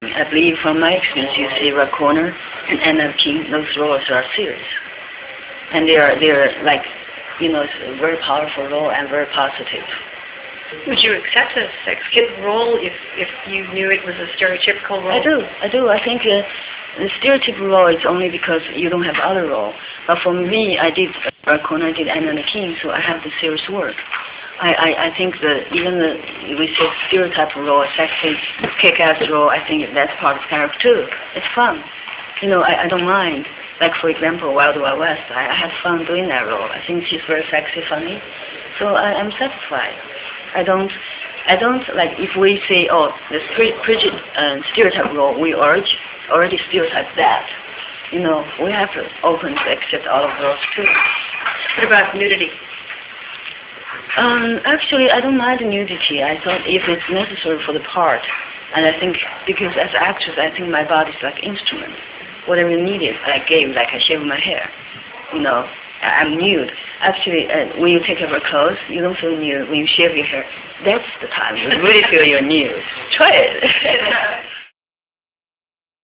Interview Highlights (Audio)
Bai_Ling_Interview3.rm